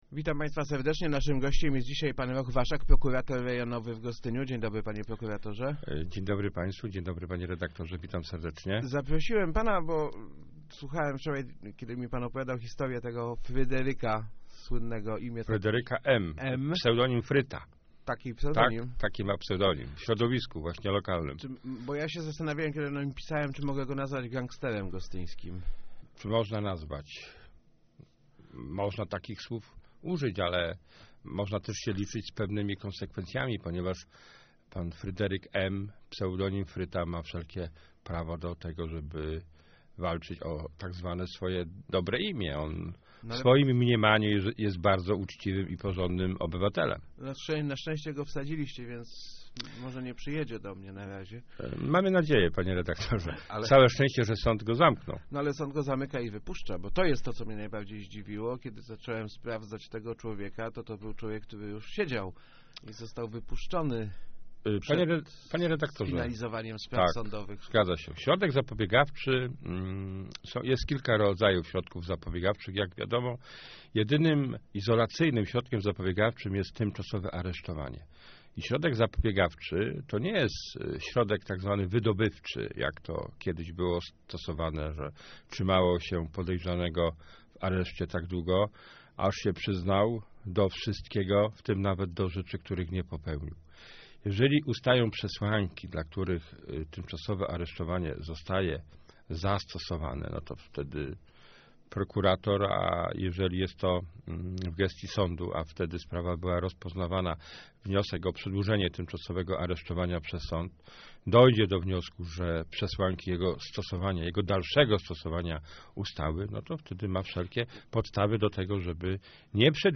rochwaszak80.jpgObserwuję u młodych ludzi upadek wszelkich autorytetów i pogardę dla prawa - mówił w Rozmowach Elki Roch Waszak, Prokurator Rejonowy w Gostyniu. Przykładem są ostatnie sprawy prowadzone w prokuraturze, dotyczące bardzo młodych przestępców, którzy w wyjątkowy sposób lekceważą swoją odpowiedzialność.